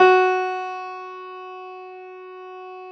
53h-pno12-F2.aif